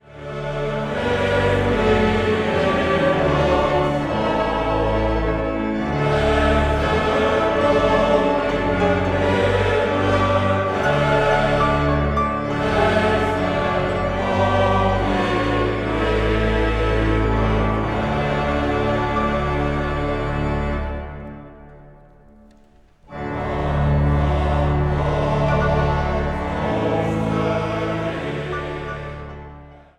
orgel
piano
trompet.
Zang | Mannenzang